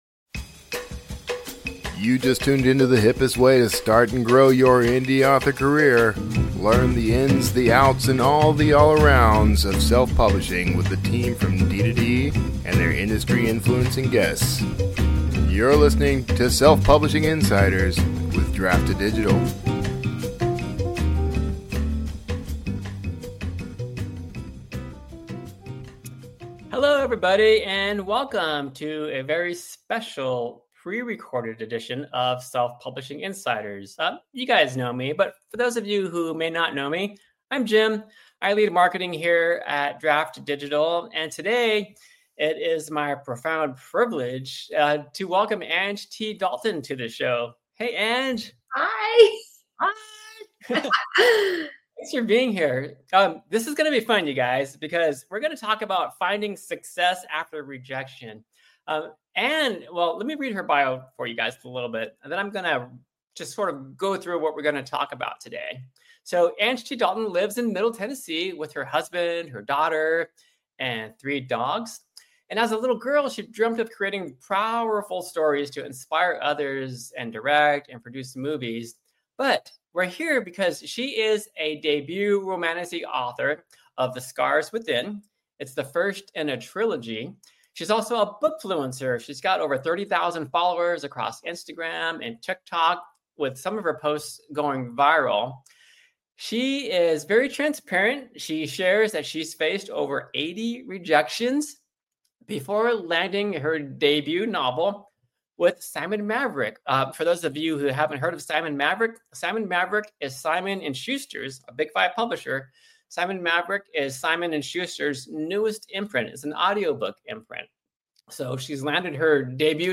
This show features interviews taken from our live D2D Spotlight, available on YouTube and Facebook.